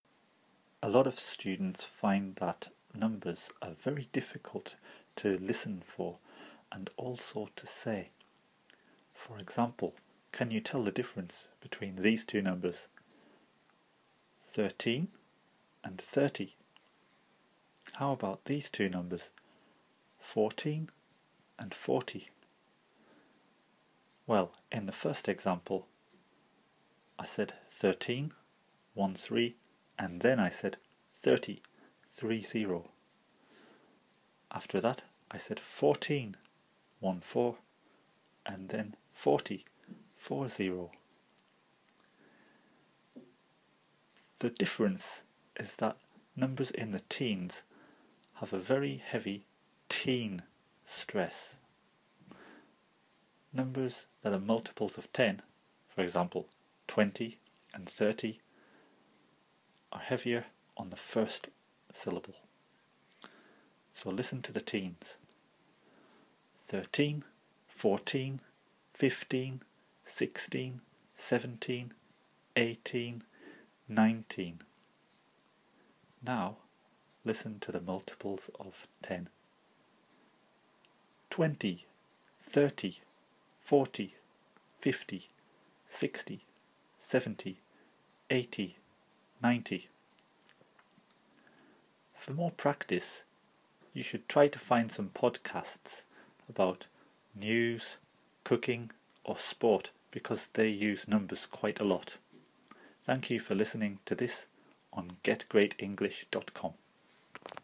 The quick way to remember them is: thirTEEN and THIRty.